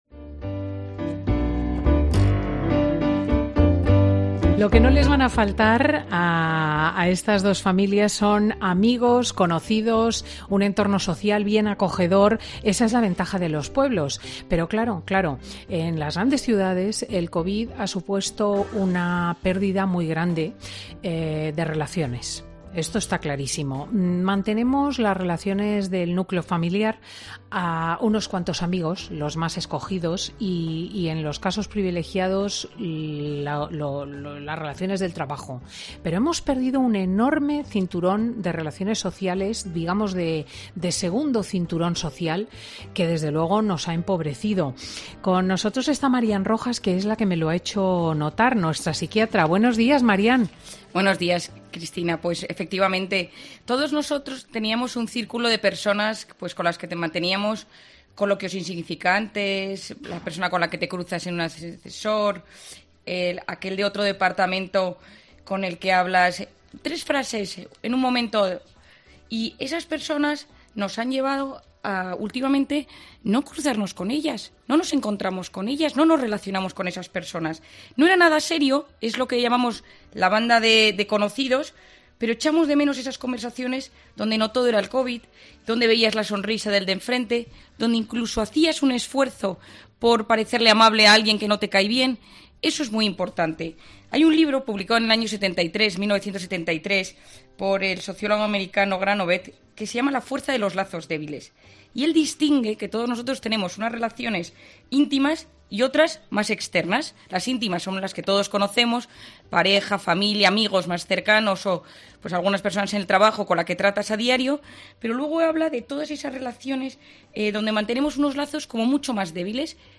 Marian Rojas explica en Fin de Semana con Cristina las consecuencias de perder nuestro círculo social externo